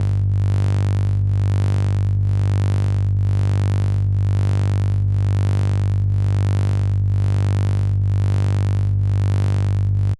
Scfi_Electric_Hum_01